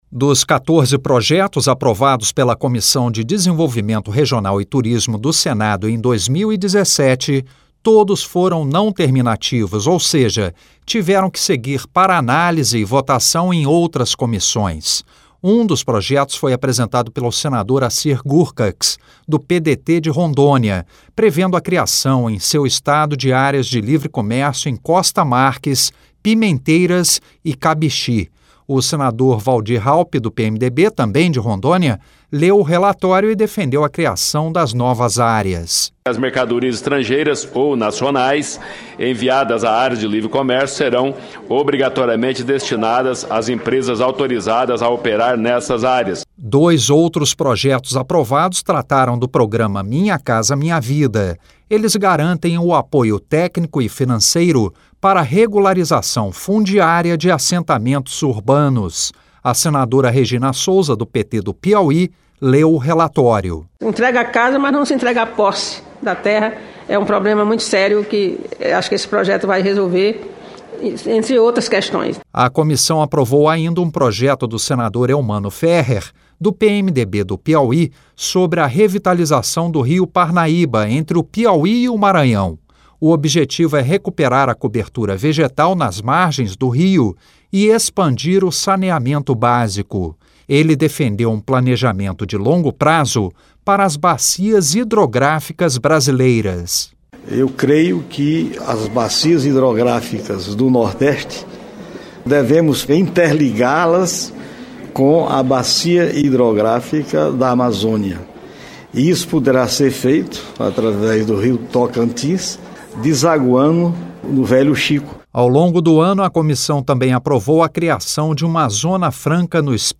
como informa o repórter da Rádio Senado